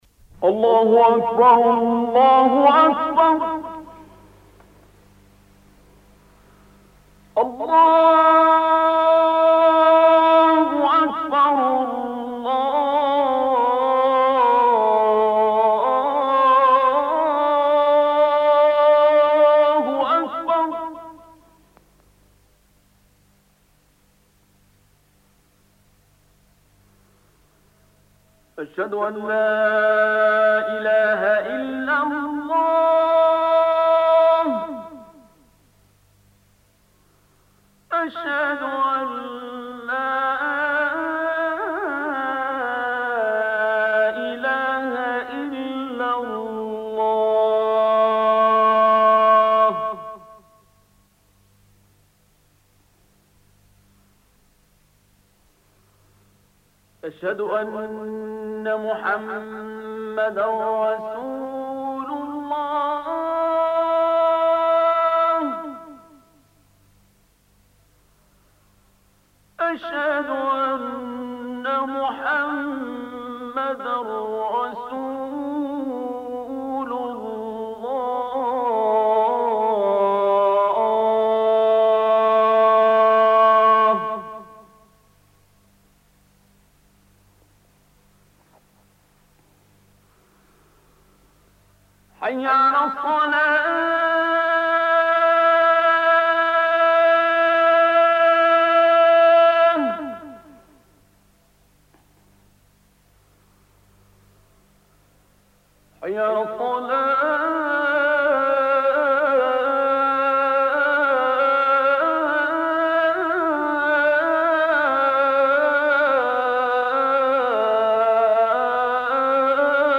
عنوان المادة أذان العشاء_الشيخ:-عبدالباسط عبدالصمـد